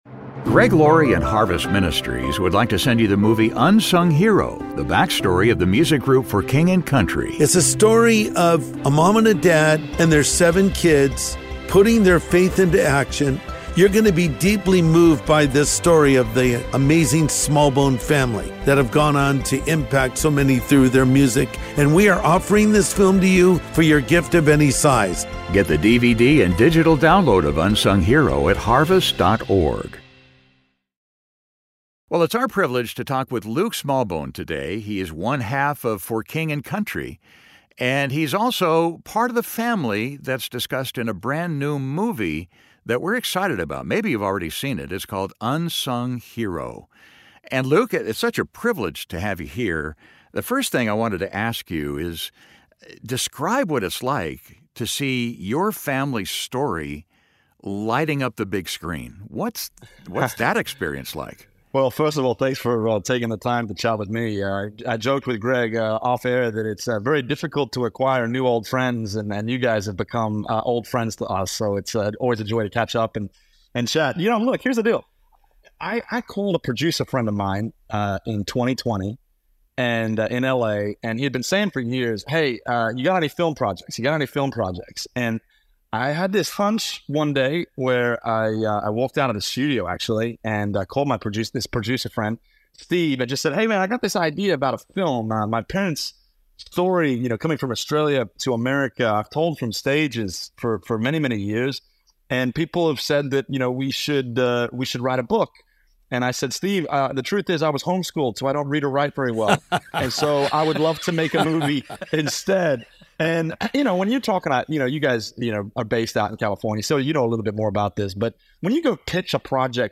Pastor Greg Laurie interviews Luke Smallbone about his new film, "Unsung Hero." It's a film that pulls at your heartstrings and brings you back to the importance of the family roles we each have, motivating us to be better spouses, parents, sons, daughters, and siblings.